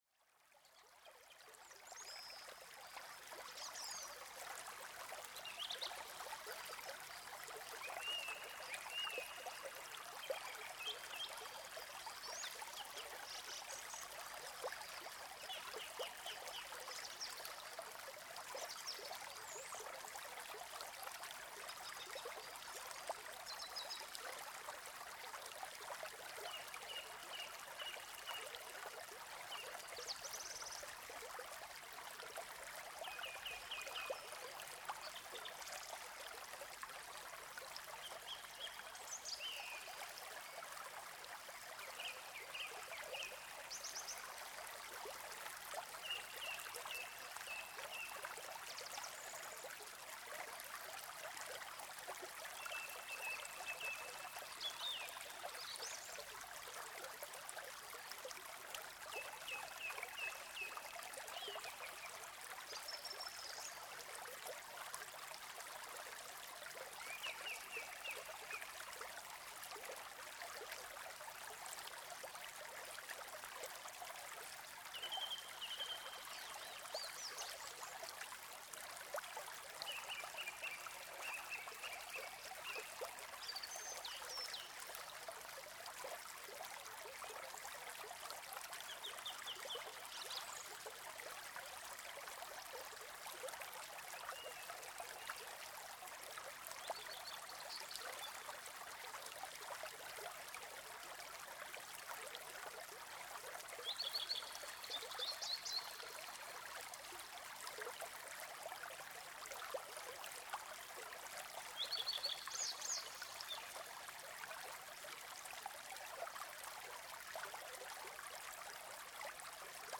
Traumhilfe: Plätschern tut gut.
Bild: Dieses Bächlein rauscht in Plars bei Algund.
Versuchen Sie es und holen Sie sich jetzt kostenlos unsere akustische Entspannungshilfe, schließen Sie die Augen und lassen Sie sich vom Plätschern des Bächleins zum Träumen entführen:
Bach-am-Waldesrand.mp3